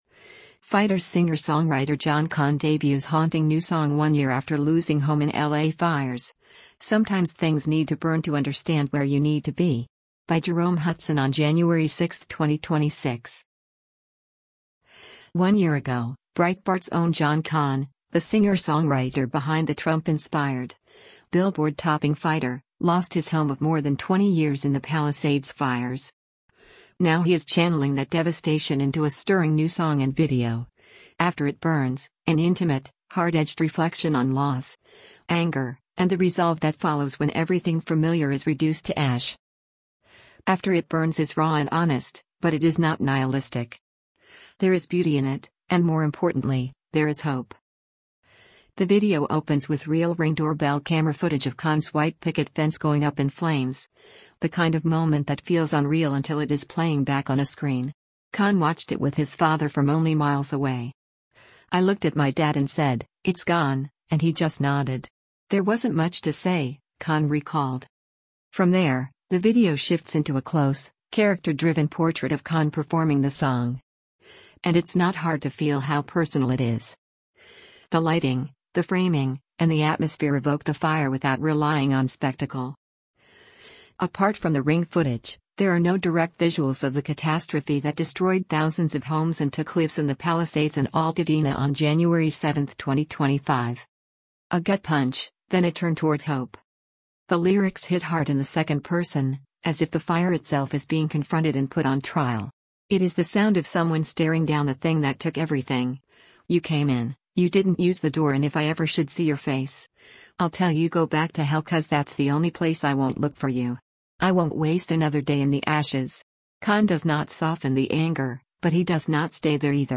There is beauty in it, and more importantly, there is hope.